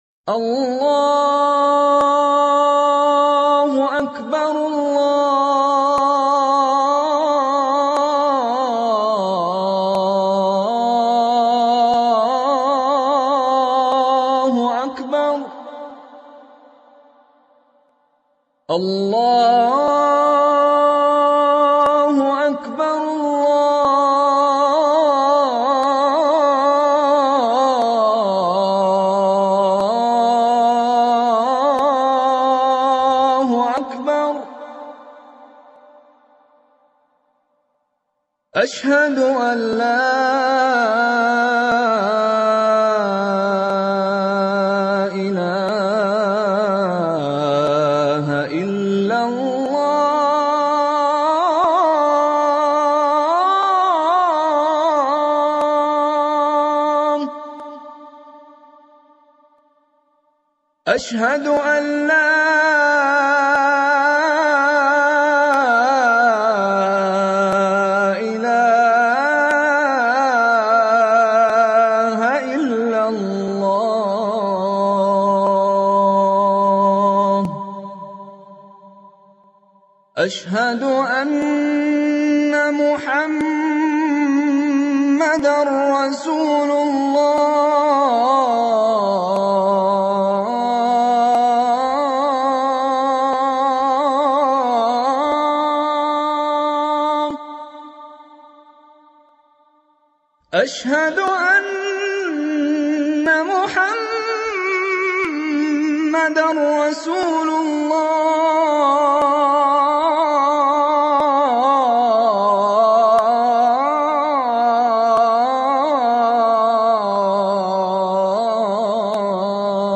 أذان القارئ الشيخ مشاري بن راشد العفاسي